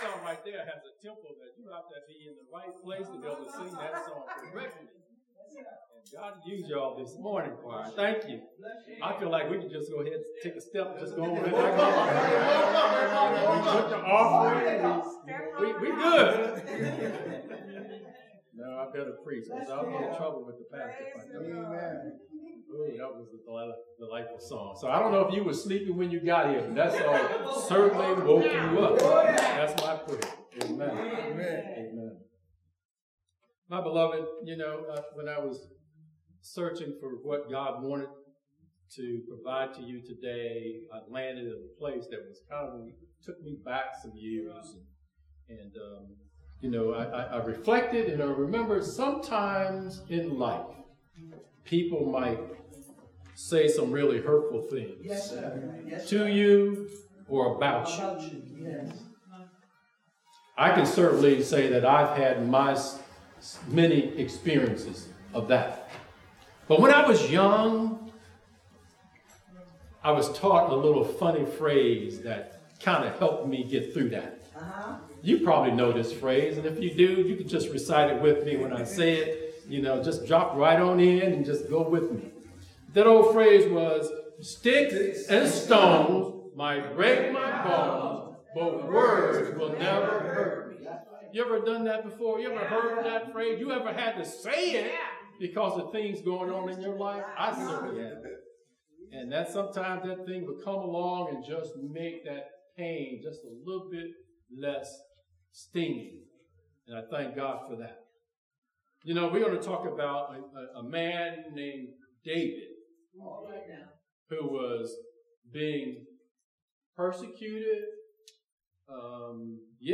Sermons | Truth Teaching Ministries